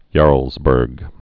(yärlzbûrg)